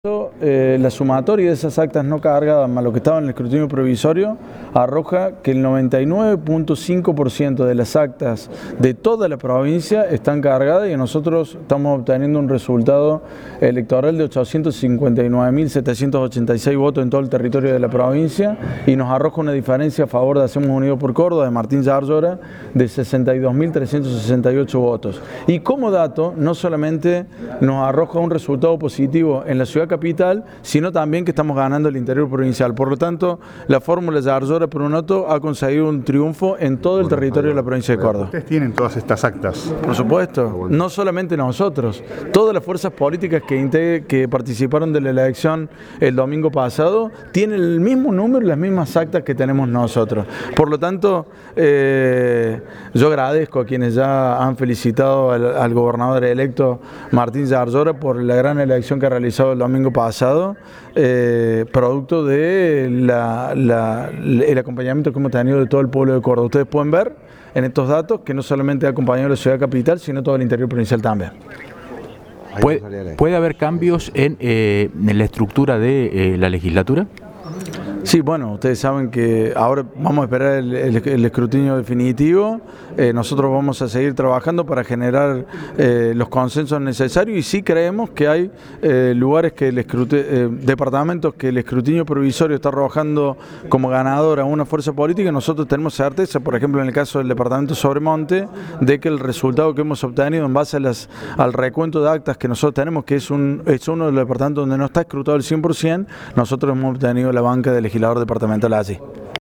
En conferencia de prensa, el vicegobernador de Córdoba Manuel Calvo, en su carácter de jefe de campaña de Hacemos Unidos por Córdoba (HUPC), ratificó el triunfo de Martín Llaryora en las elecciones del domingo pasado en la provincia.
Audio: Manuel Calvo (Vicegobernador de Córdoba y Jefe de Campaña HUPC).